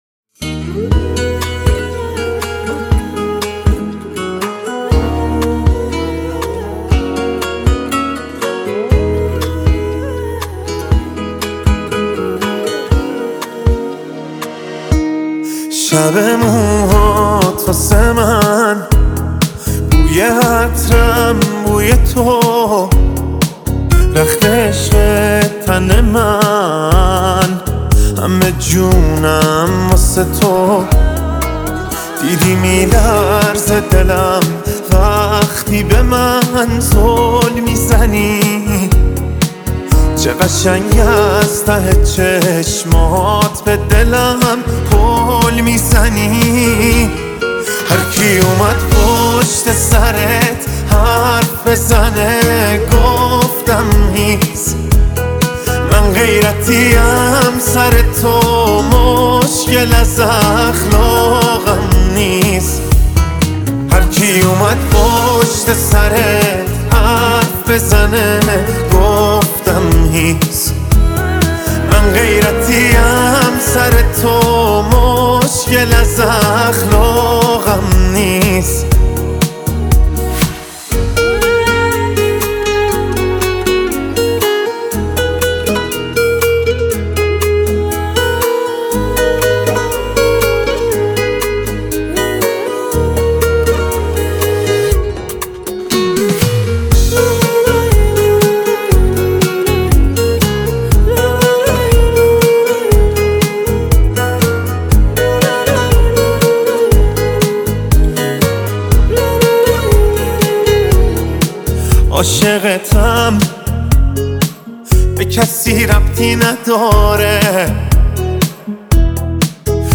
خواننده موسیقی پاپ